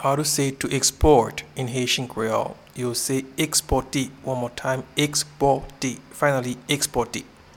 Pronunciation and Transcript:
to-Export-in-Haitian-Creole-Ekspote.mp3